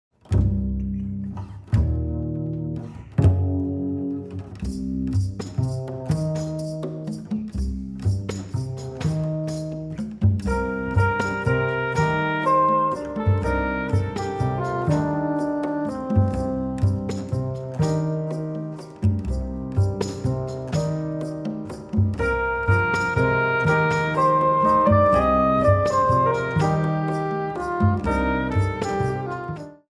Soprano Sax, Piano, Bass, Percussion
Improvisation is the cloth of these works are cut from.